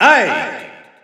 Announcer pronouncing Ike in French PAL.
Ike_French_EU_Announcer_SSBU.wav